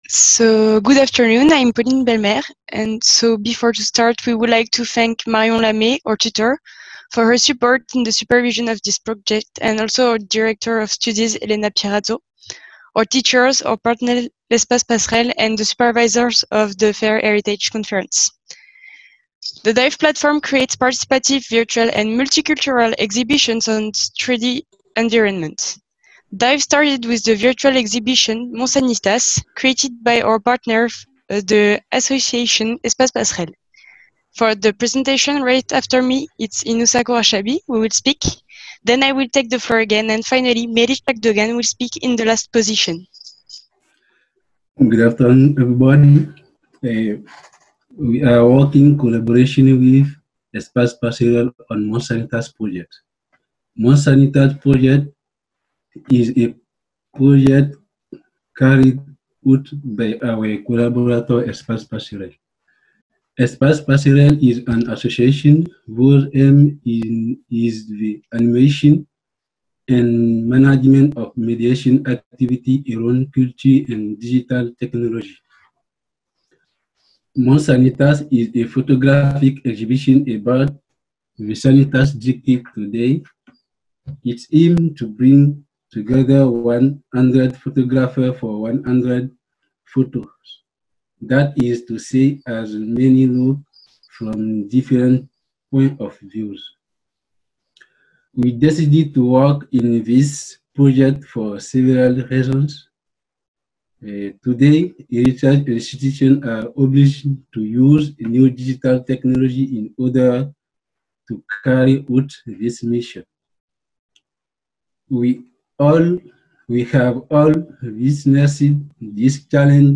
Flash talk